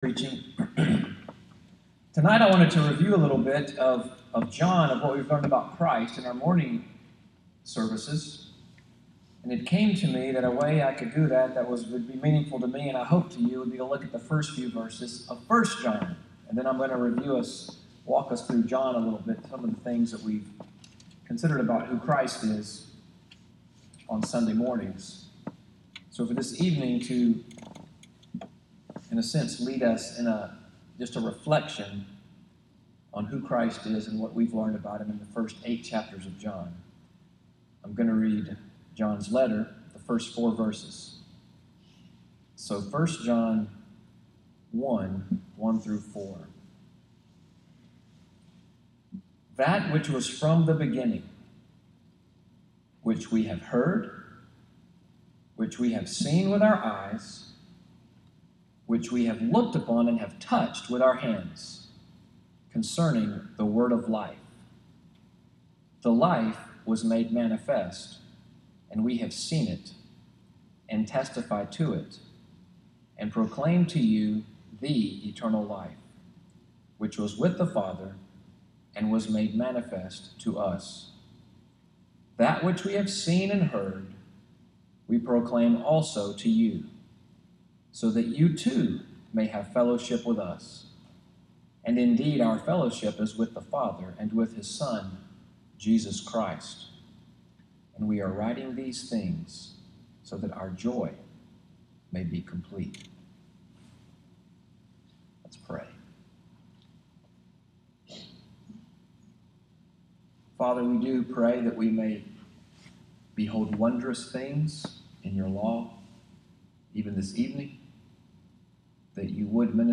SUNDAY EVENING WORSHIP at NCPC, for June 11, 2017, audio of sermon “The Eternal Life.”